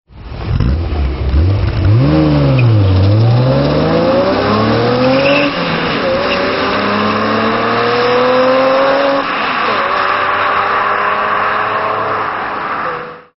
Sons de moteurs bmw - Engine sounds bmw - bruit V8 V10 bmw